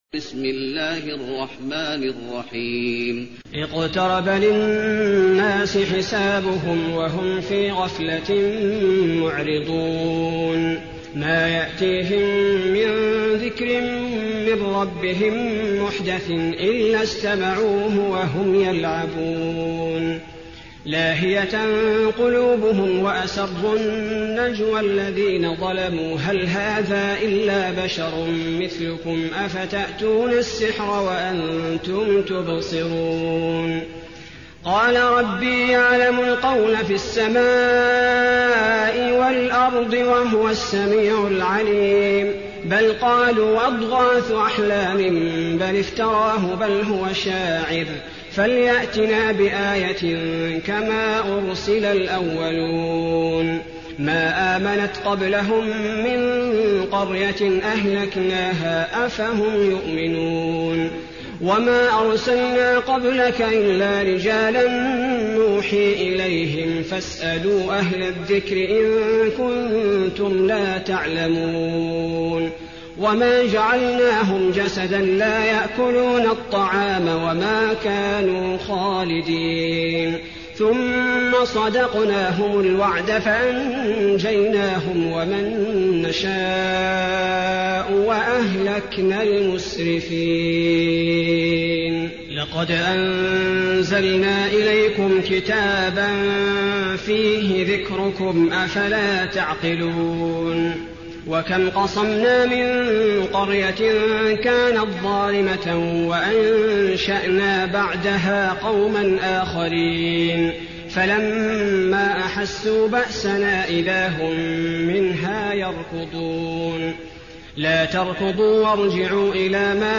المكان: المسجد النبوي الأنبياء